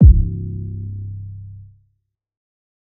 Metro 808s [Auto].wav